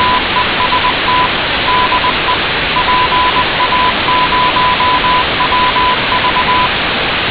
Hierboven zie je een allesoverheersende ruis.